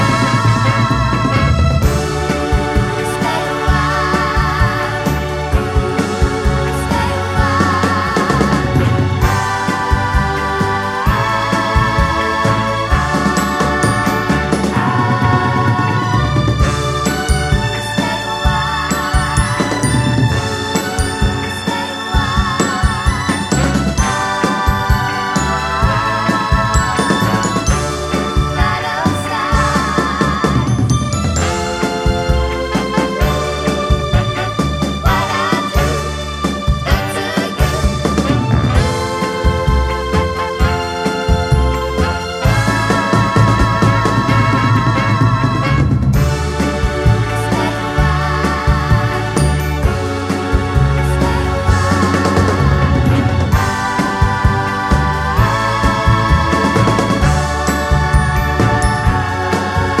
no Backing Vocals Pop (1960s) 1:57 Buy £1.50